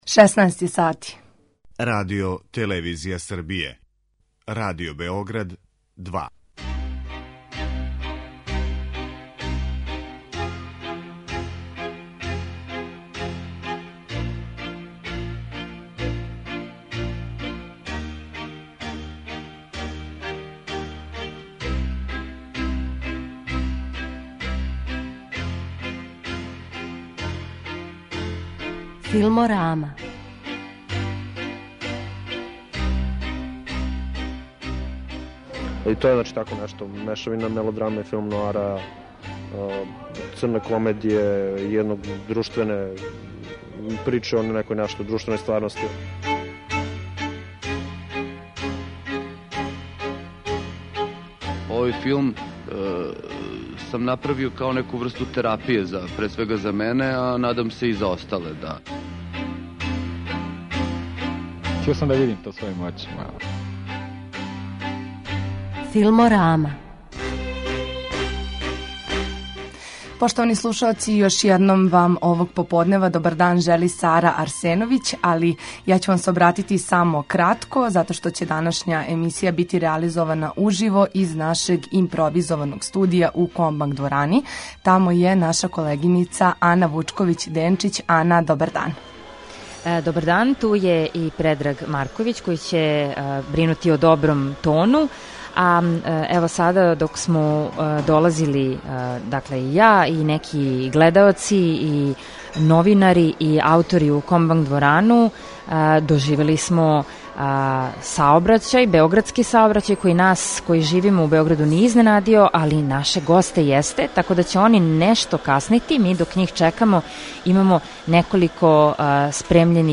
Уживо из нашег студија у Комбанк дворани
Данашња емисија биће реализована уживо из нашег студија у Комбанк дворани, једном од места у коме се одвија 48. Међународни филмски фестивал ФЕСТ.